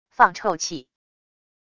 放臭气wav音频